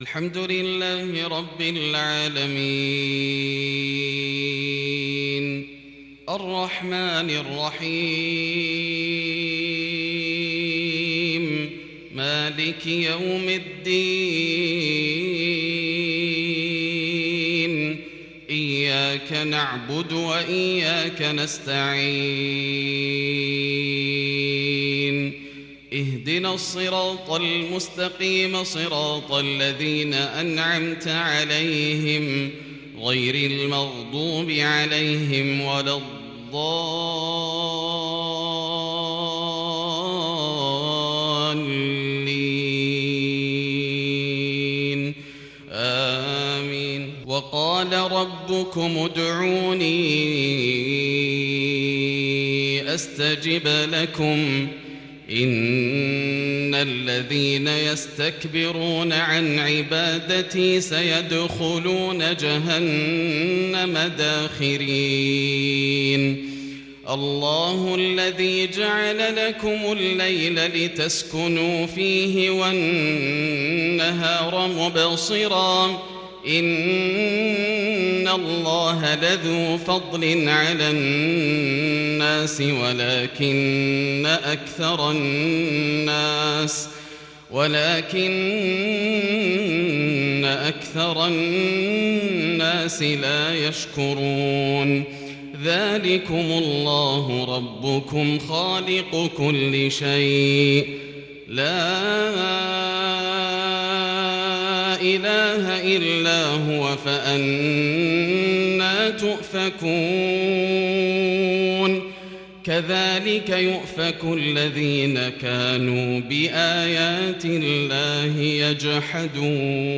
صلاة المغرب للشيخ ياسر الدوسري 5 ربيع الآخر 1442 هـ
تِلَاوَات الْحَرَمَيْن .